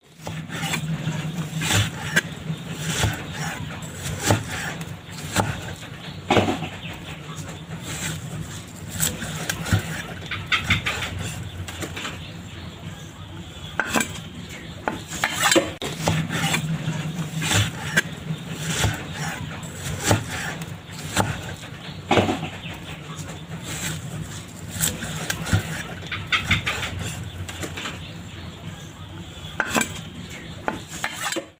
Tiếng dùng dao Thái Thịt trên thớt
Tiếng Chặt Thịt đều đặn trên thớt YES!!! sound effect
Thể loại: Tiếng ăn uống
Description: Tiếng cắt thịt, tiếng xắt thịt, tiếng lạng thịt, tiếng dao lướt thớt, tiếng dao thái, âm thanh “xẹt… xẹt” hoặc “cắt… cắt” đều tay khi lưỡi dao sắc lướt qua từng thớ thịt, chạm nhẹ xuống bề mặt thớt gỗ hay thớt nhựa.
tieng-dung-dao-thai-thit-tren-thot-www_tiengdong_com.mp3